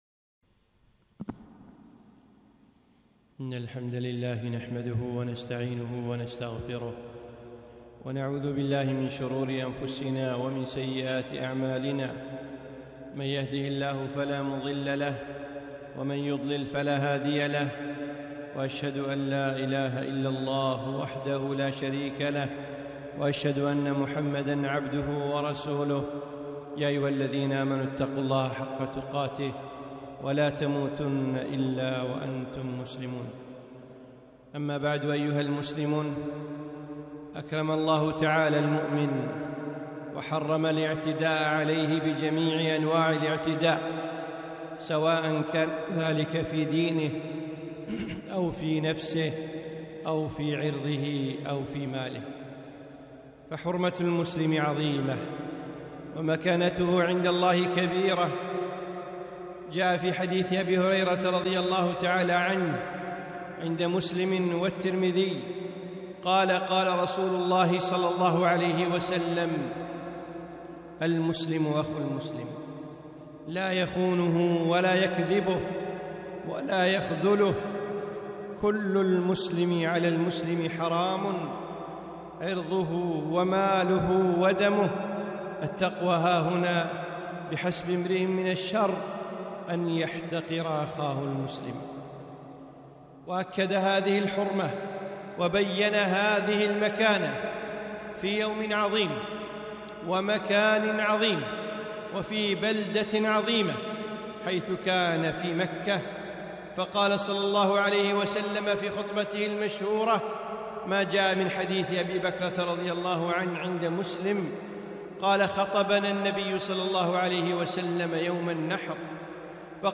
خطبة - سفك الدم الحرام